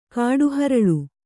♪ kāḍu haraḷu